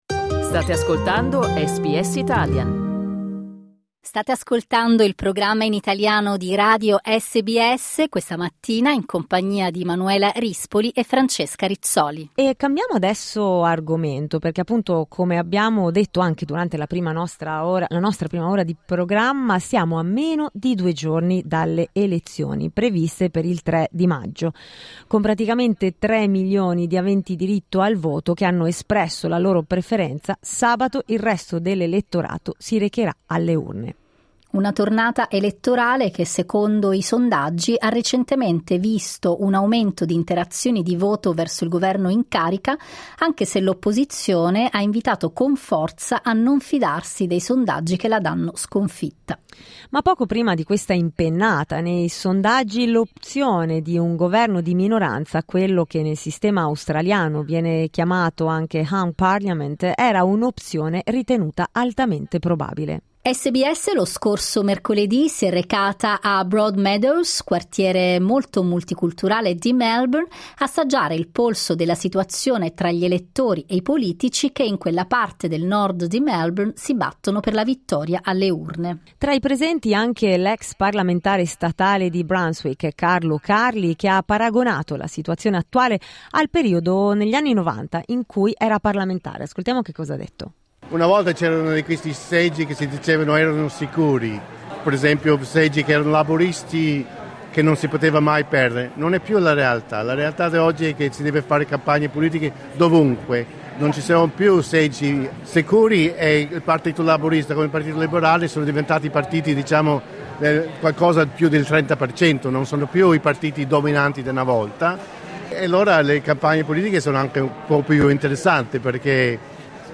Ascolta il servizio di SBS Italian cliccando il tasto "play' in alto a sinistra